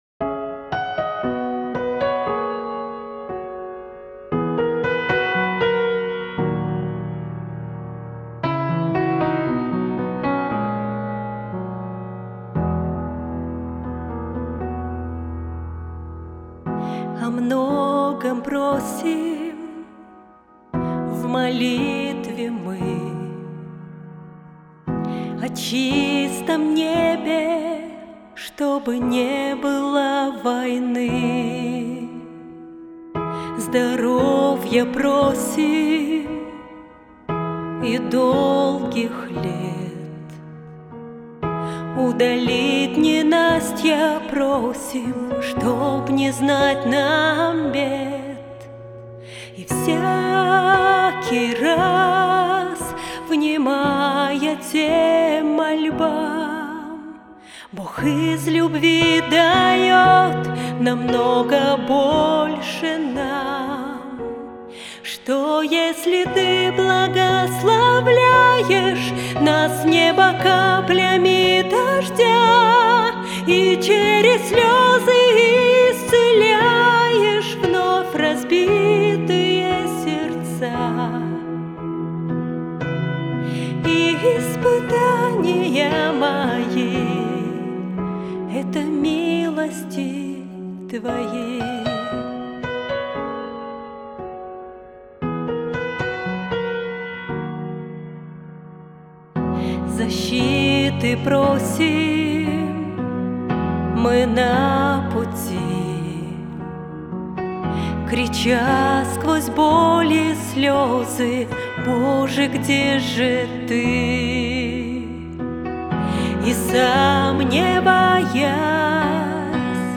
песня
426 просмотров 164 прослушивания 17 скачиваний BPM: 119